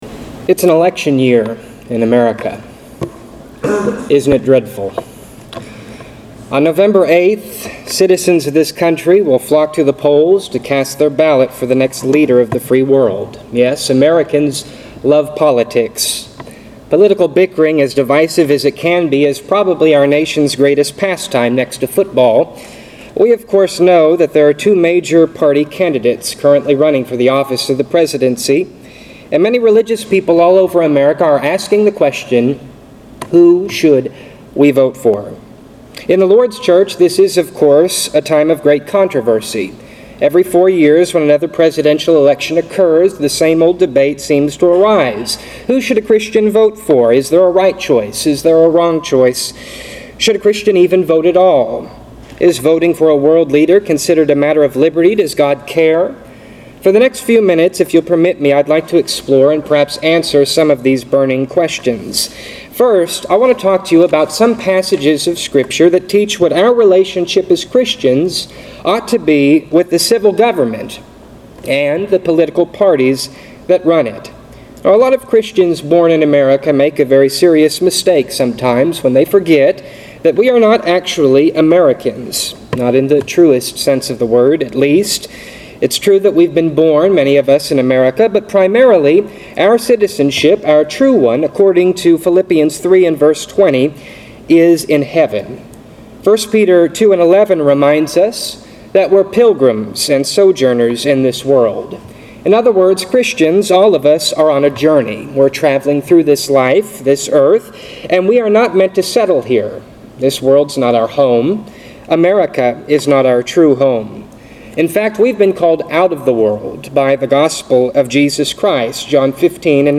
Millions of Bible-believing people are wondering which candidate deserves their time, money, and eventually, their vote. In this sermon